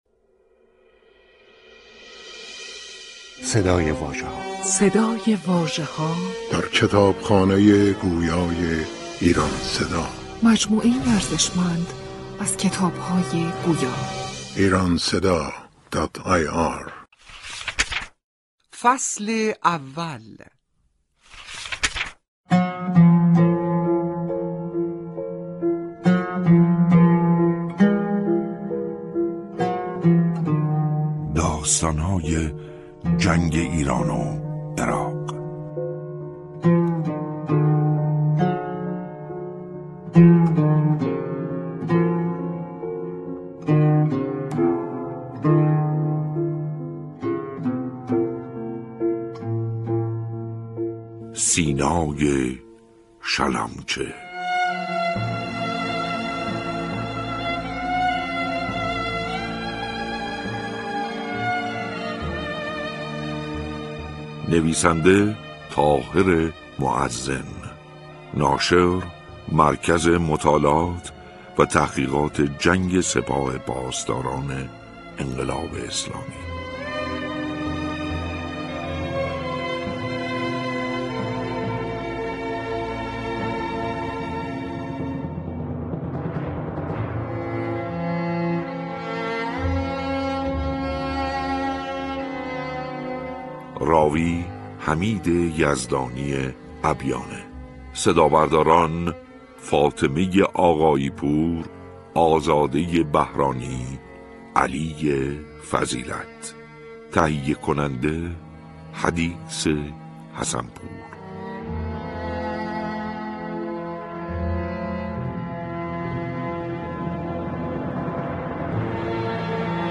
کتاب گویای «سینای شلمچه» تهیه و بر روی پایگاه کتاب گویای ایران صدا در دسترس علاقه‌مندان قرارگرفته است.